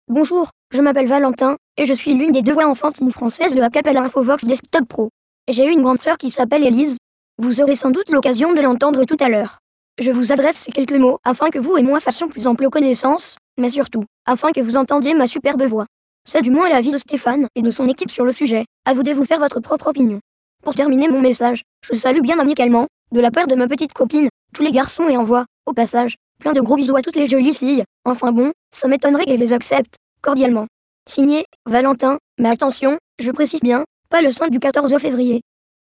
Texte de démonstration lu par Valentin, voix enfantine française d'Acapela Infovox Desktop Pro
Écouter la démonstration de Valentin, voix enfantine française d'Acapela Infovox Desktop Pro